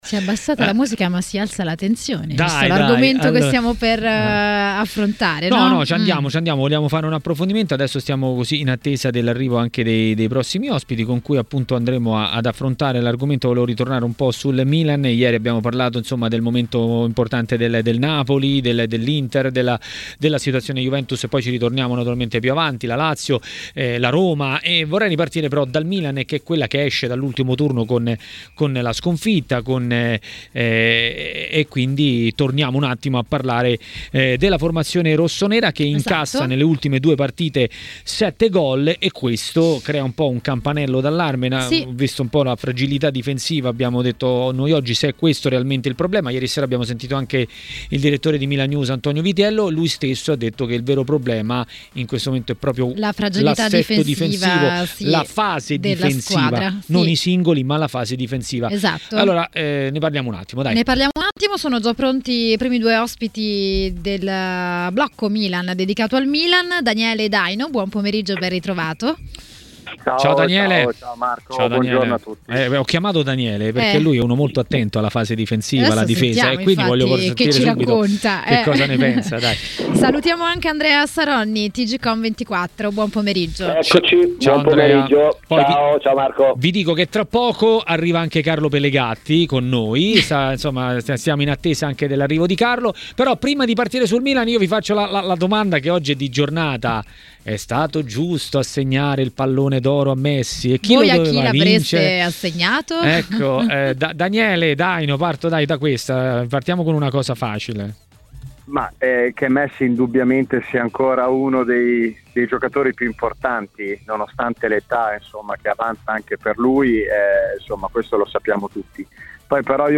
A parlare del momento difficile del Milan a Maracanà, nel pomeriggio di TMW Radio, è stato l'ex calciatore e tecnico Daniele Daino.